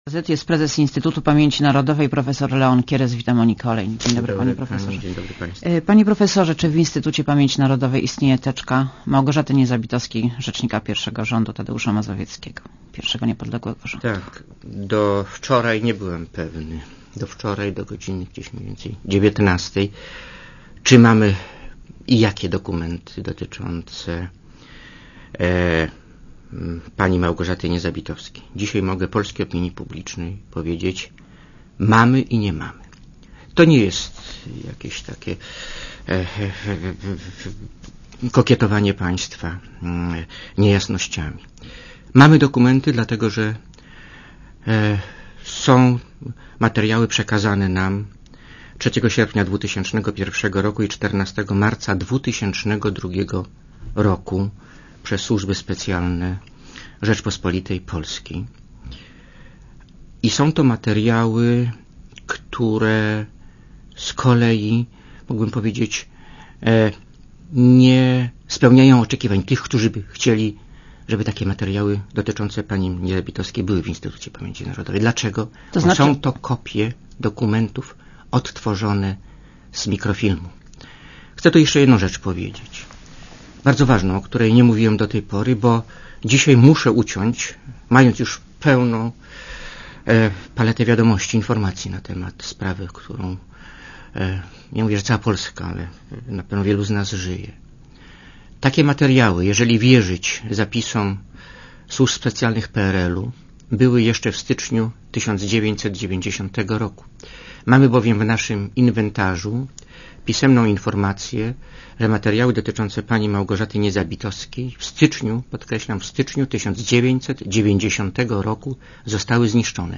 Posłuchaj wywiadu Gościem Radia Zet jest prezes IPN –u, prof. Leon Kieres.